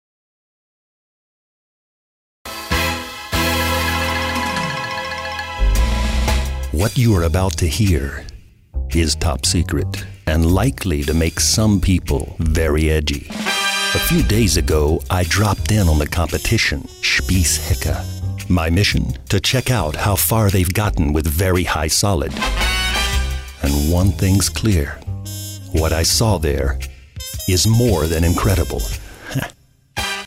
American Speaker, off speaker, advertizing, Internet, Industry, TV....Voice color: Middle to deep.
Sprechprobe: Werbung (Muttersprache):